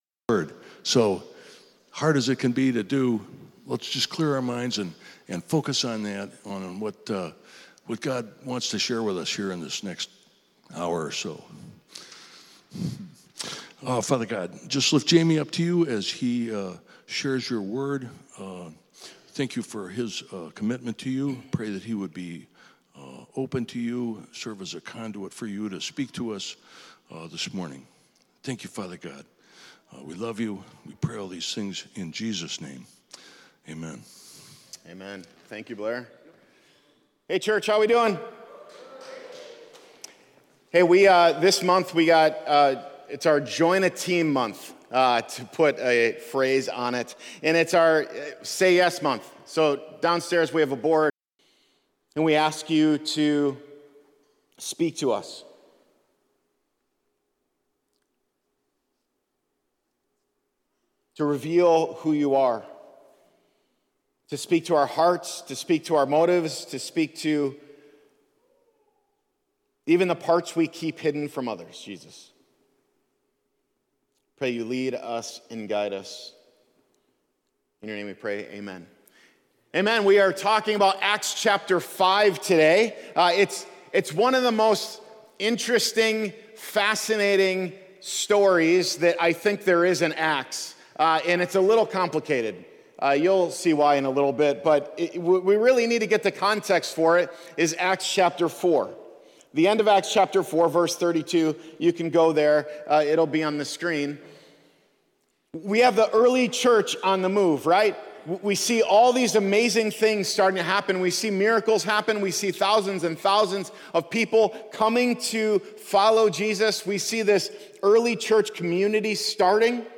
Religion Sunday Service Renew Church Christianity Content provided by Renew Church.